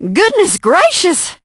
piper_die_01.ogg